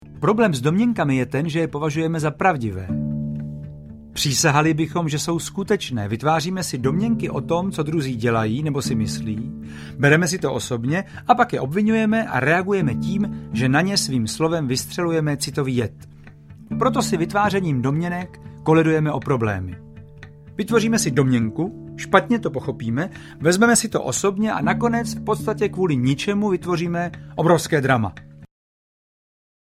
Čtyři dohody audiokniha
Ukázka z knihy
• InterpretJaroslav Dušek